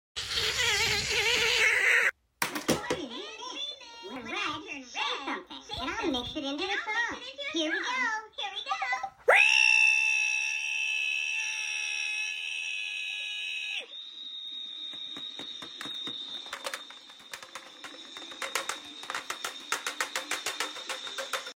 Double REEE at the toys sound effects free download